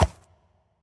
Minecraft Version Minecraft Version latest Latest Release | Latest Snapshot latest / assets / minecraft / sounds / mob / horse / skeleton / water / soft2.ogg Compare With Compare With Latest Release | Latest Snapshot